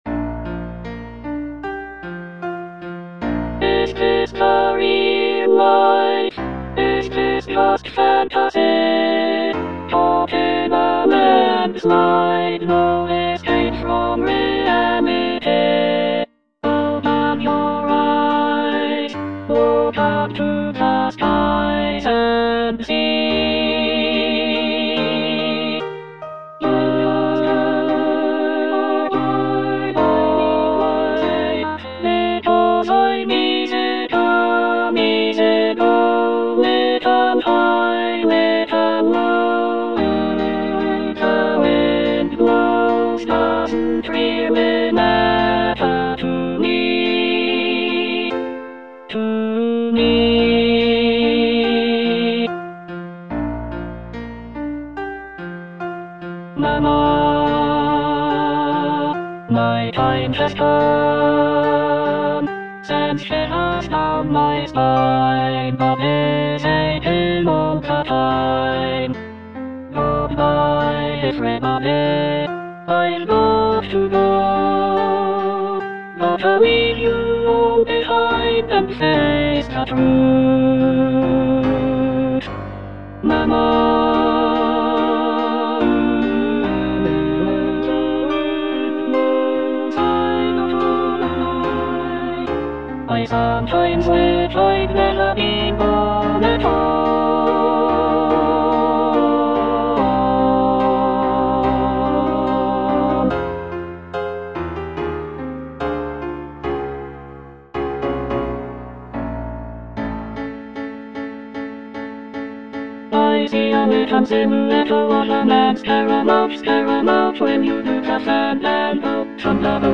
Alto II (Emphasised voice and other voices)